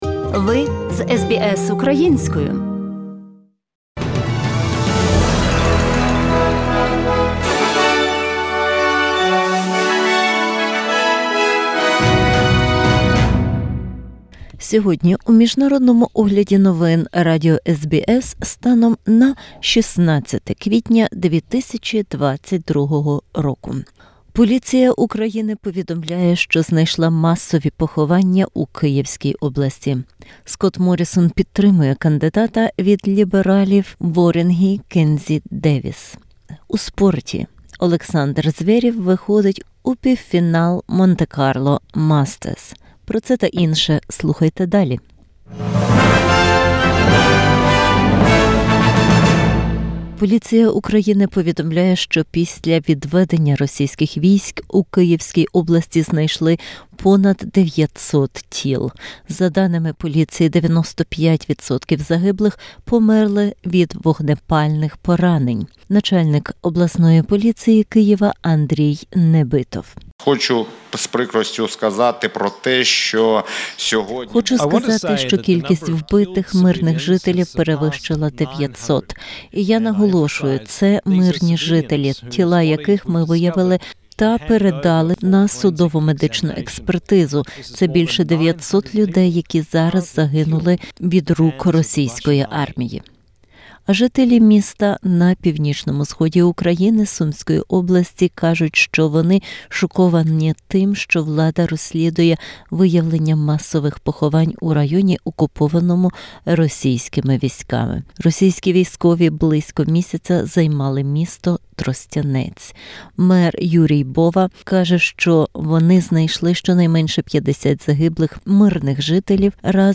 Бюлетень новин SBS українською мовою. Поліція України повідомляє, що знайшла масові поховання в Київській області.